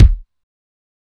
Kick